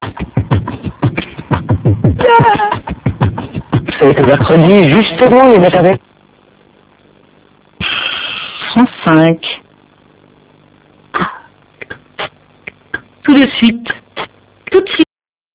:!: Débit inégal.